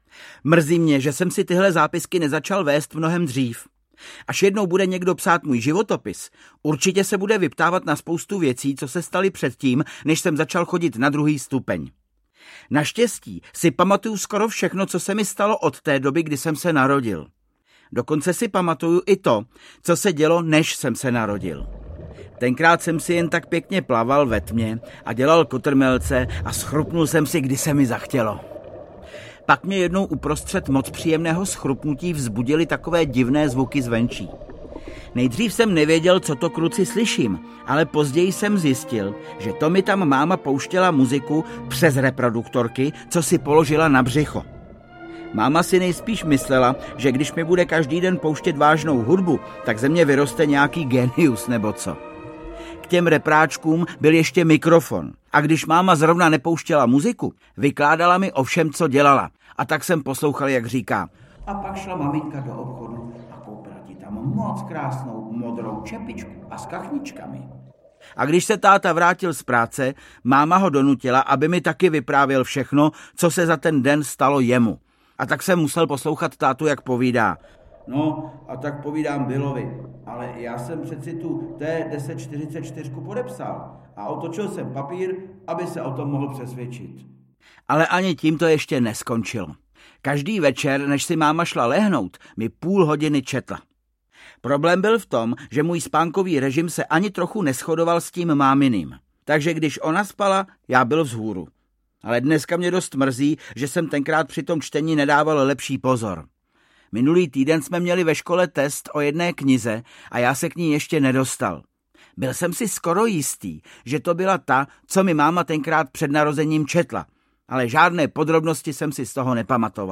Audio knihaDeník malého poseroutky 7 - Páté kolo u vozu
Ukázka z knihy
• InterpretVáclav Kopta